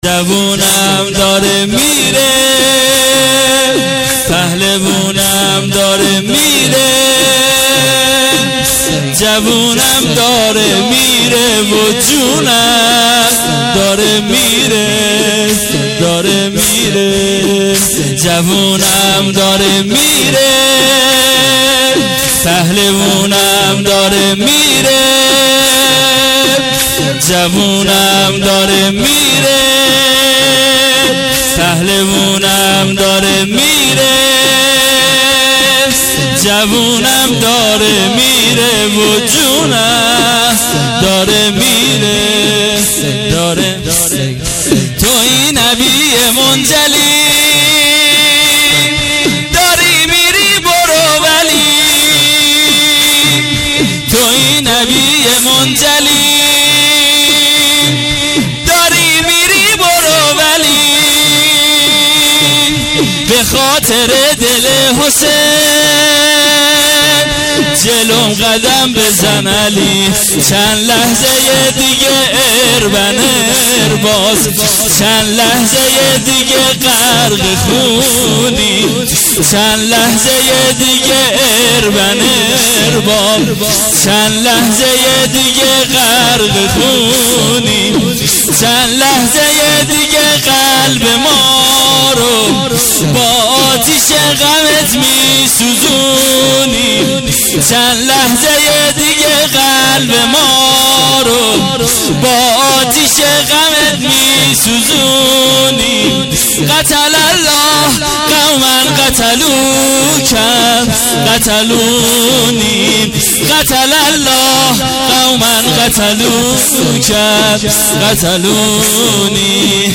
شب هشتم محرم الحرام ۱۳۹۸